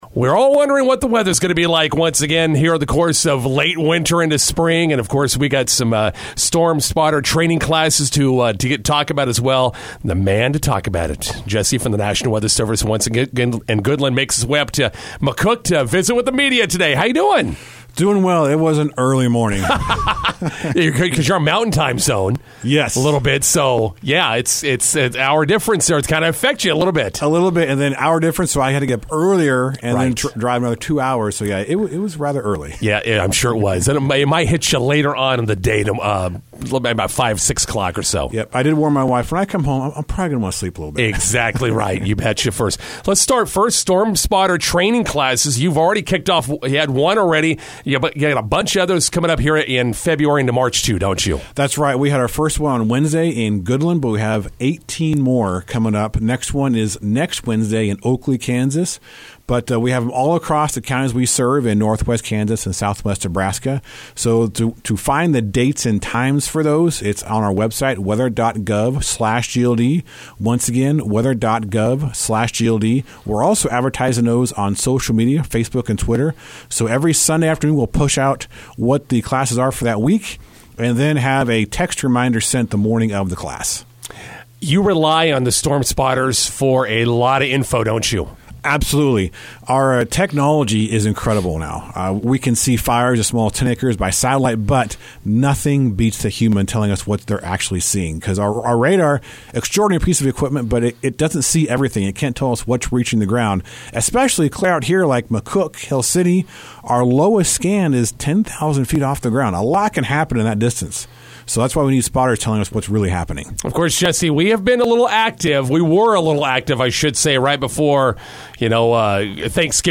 INTERVIEW: National Weather Service in Goodland offering storm spotter training classes in February and March.